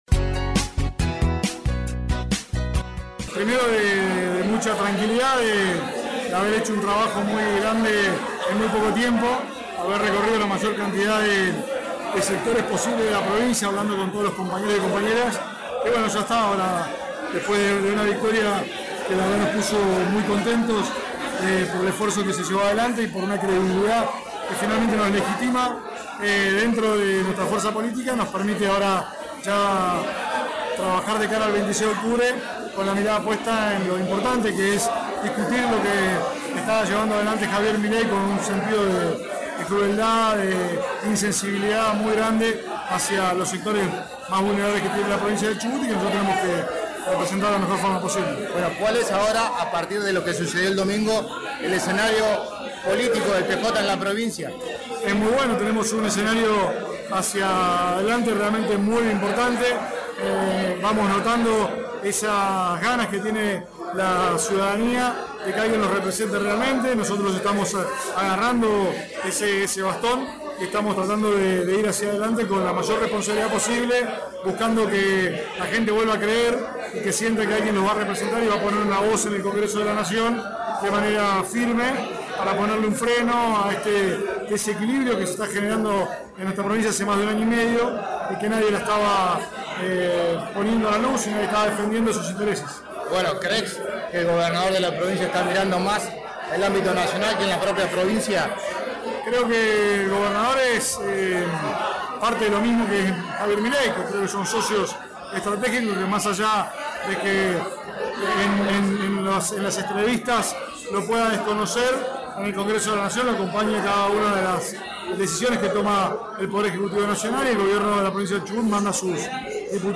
El ya candidato a diputado nacional por el justicialismo, Juan Pablo Luque, brindó una conferencia de prensa en el valle y posteriormente dialogó con RADIOVISIÓN